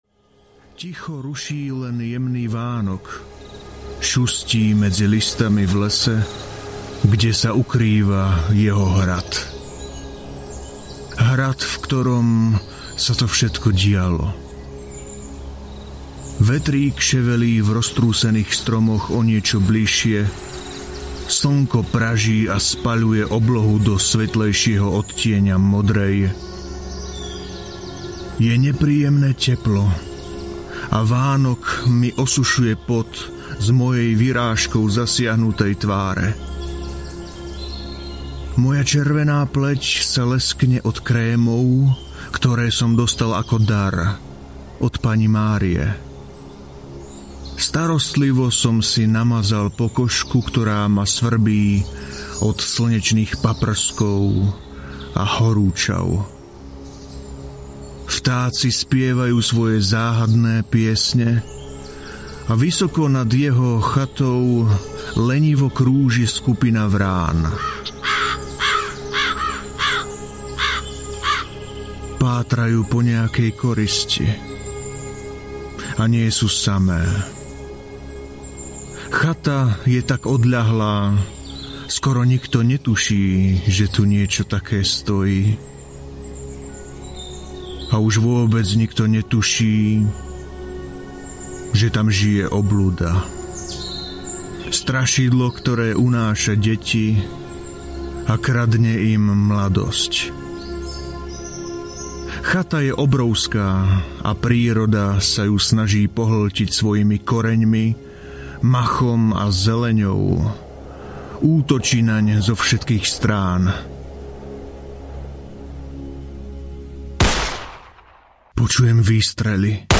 Luki I. audiokniha
Ukázka z knihy